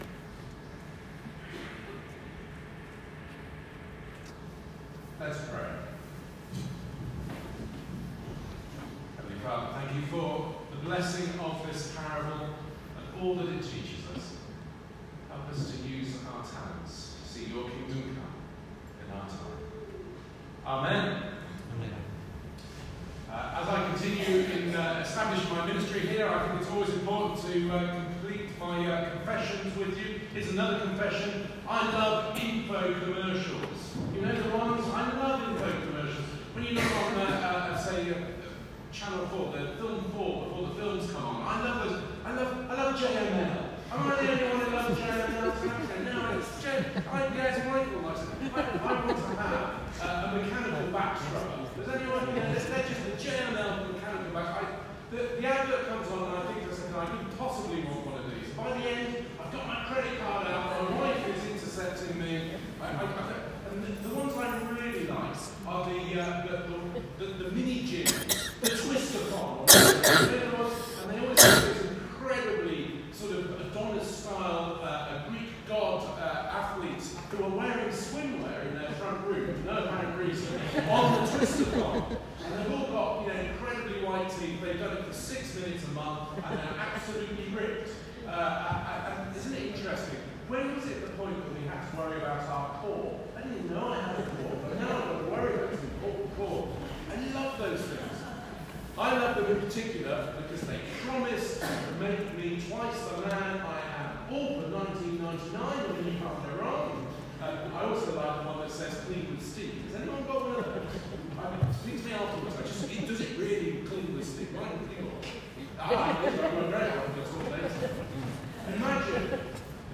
Sermon-On-Talents.m4a